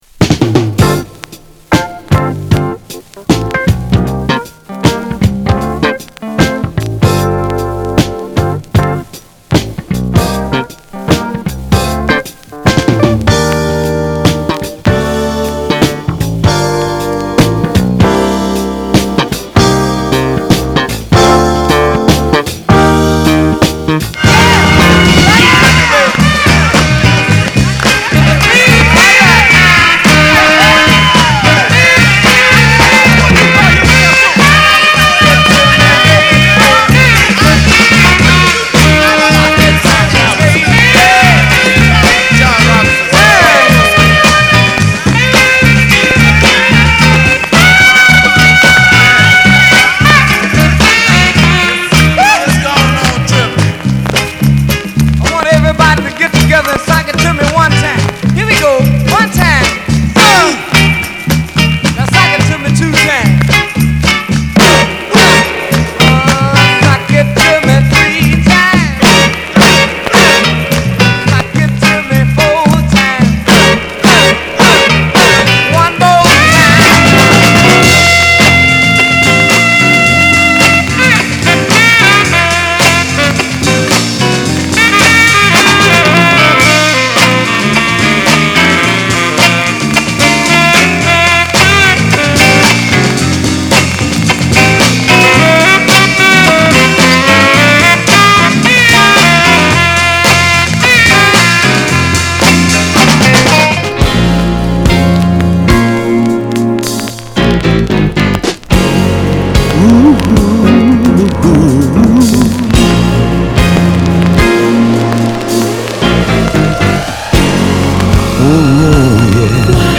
/盤質/両面全体的に細かい傷あり/US PRESS